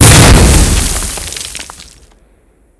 GrenExpl03.wav